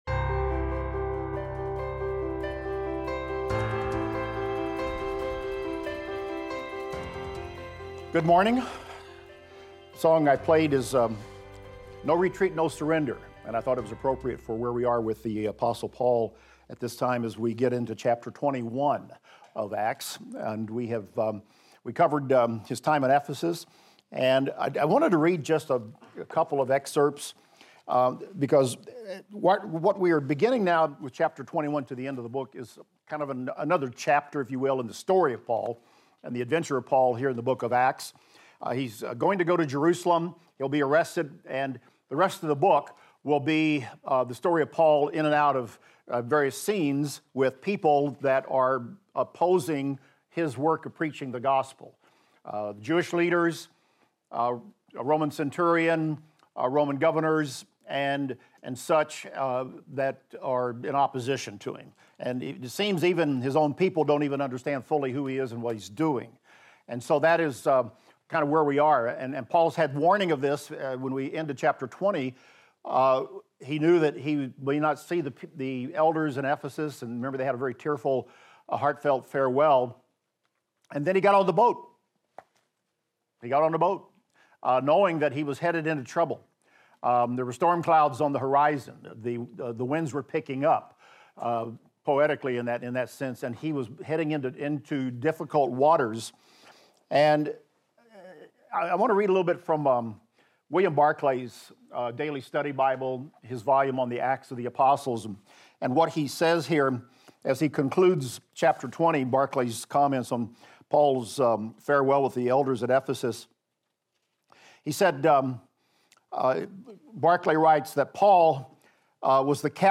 In this class we will discuss Acts 21:1-25 and examine the following: Paul sets sail from Miletus and travels to various cities, eventually arriving in Tyre.